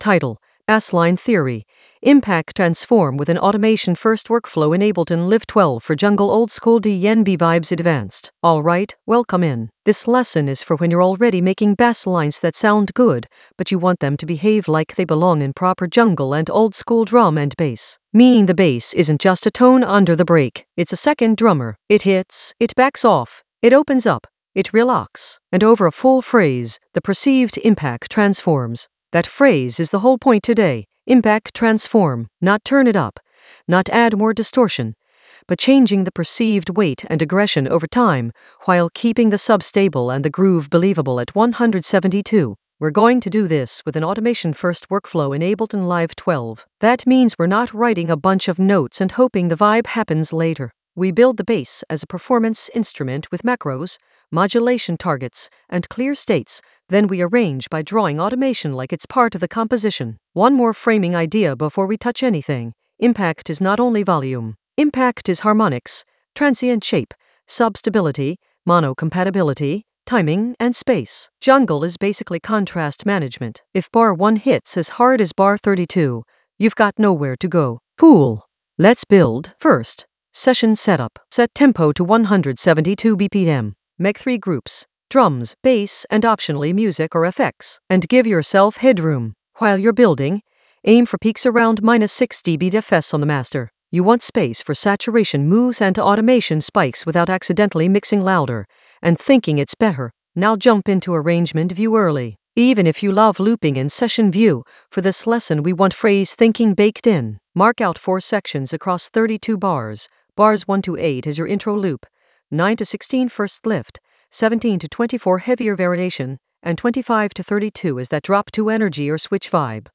Narrated lesson audio
The voice track includes the tutorial plus extra teacher commentary.
An AI-generated advanced Ableton lesson focused on Bassline Theory: impact transform with automation-first workflow in Ableton Live 12 for jungle oldskool DnB vibes in the Breakbeats area of drum and bass production.